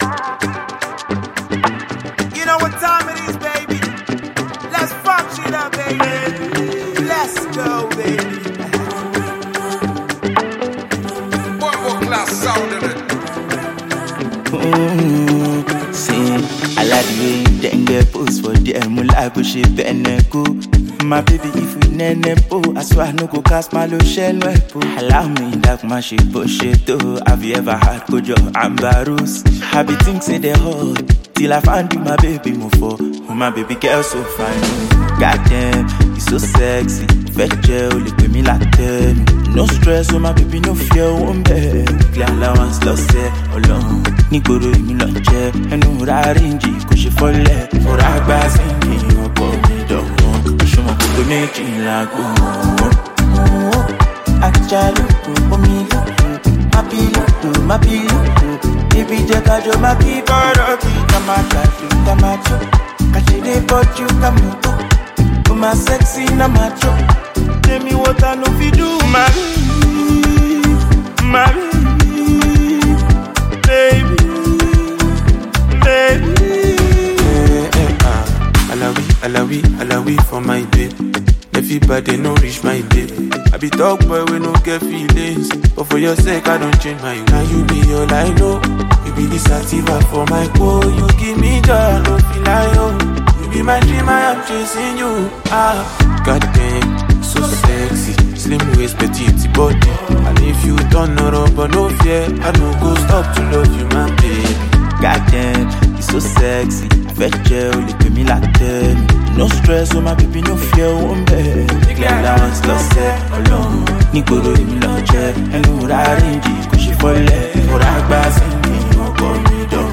street-pop
a perfect blend of Afro-fusion and street energy